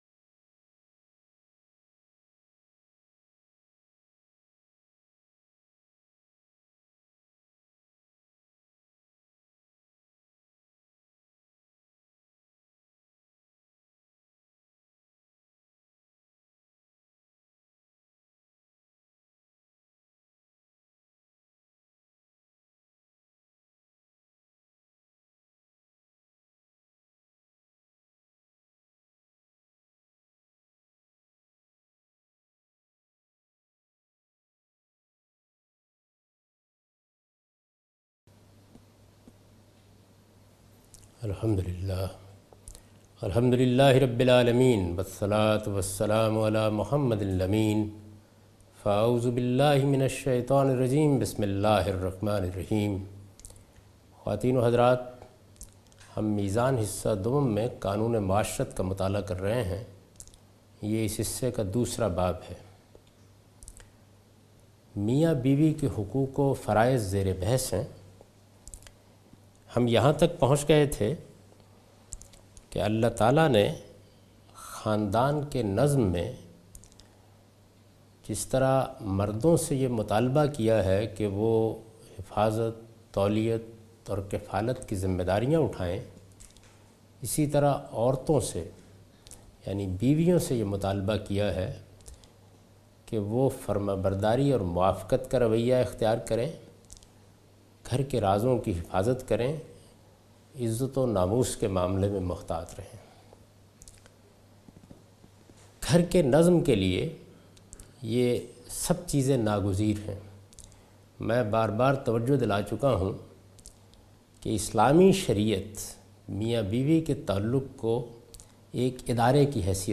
A comprehensive course on Islam, wherein Javed Ahmad Ghamidi teaches his book ‘Meezan’.